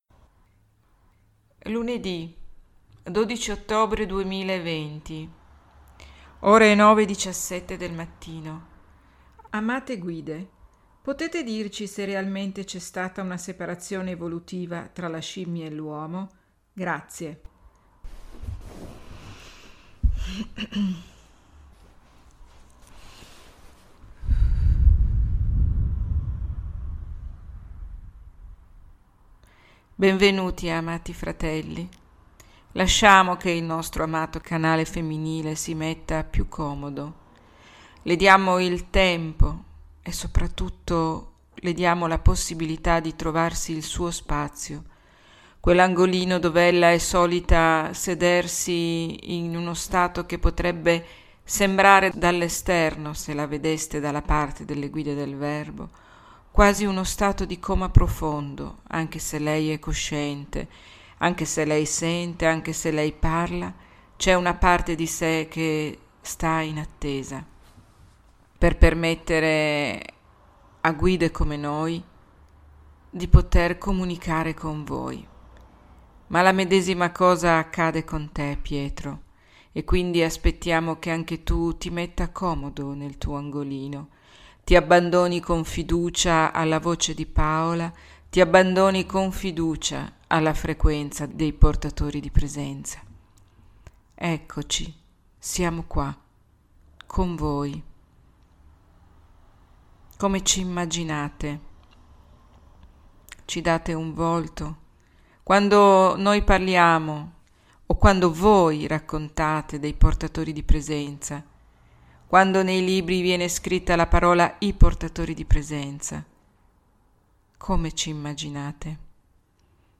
____________________________________ Luned� 12 ottobre 2020 l'anello mancante: L'UOMO - Fare Pace tra Spirito e Materia - [l'audio della sessione di channeling] Ore ~ 9,17 �Amate Guide, potete dirci se realmente c'� stata una separazione evolutiva tra la scimmia e l'Uomo?� Benvenuti, Amati fratelli!